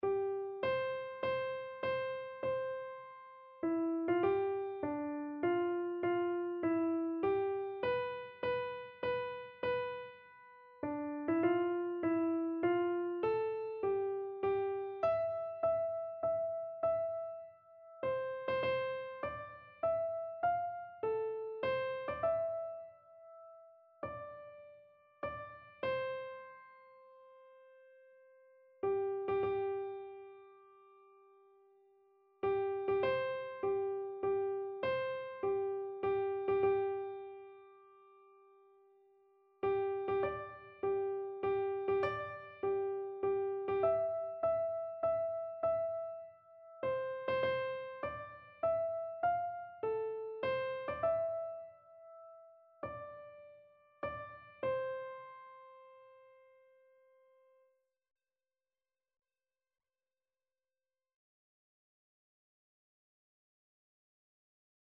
Keyboard version
Christian
Free Sheet music for Keyboard (Melody and Chords)
3/4 (View more 3/4 Music)
Keyboard  (View more Intermediate Keyboard Music)
Classical (View more Classical Keyboard Music)